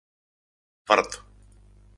Pronunciado como (IPA) /ˈfaɾto̝/